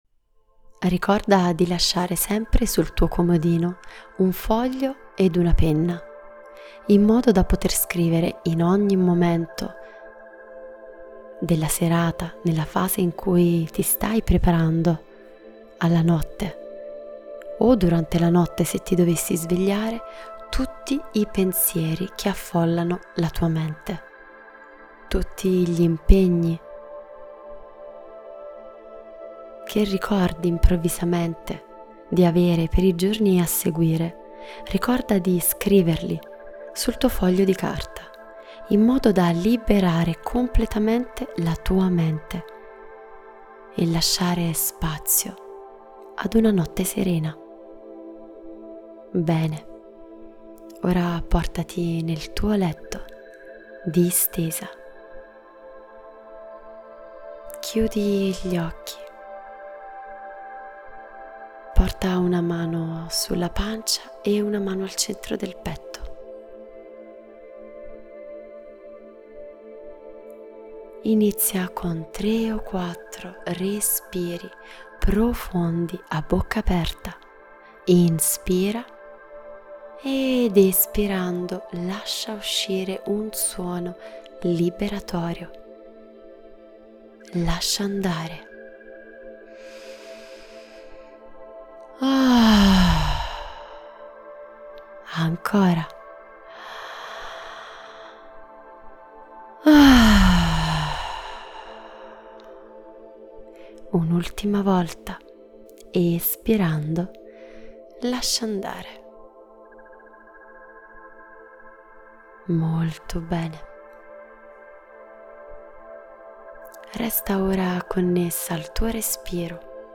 RILASSAMENTO-PER-DORMIRE-TUTTA-LA-NOTTE.mp3